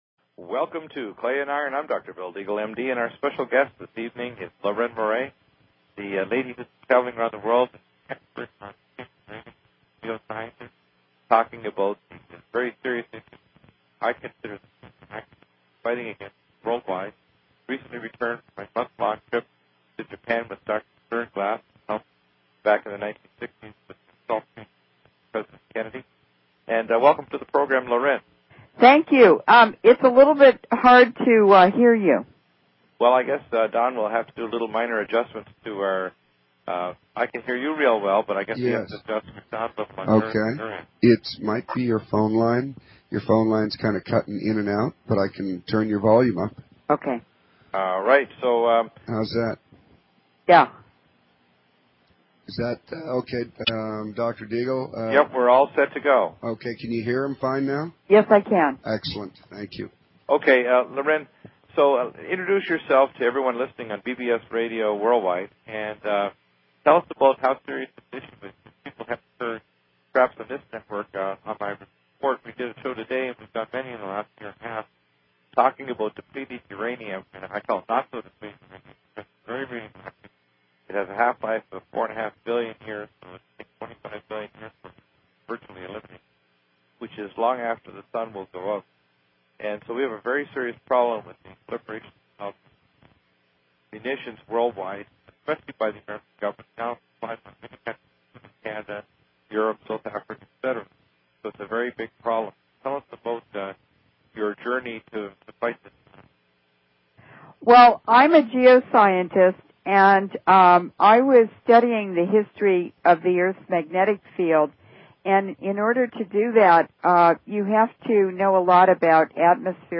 Talk Show Episode, Audio Podcast, Clay_Iron and Courtesy of BBS Radio on , show guests , about , categorized as